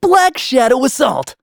Kibera-Vox_Skill7_b.wav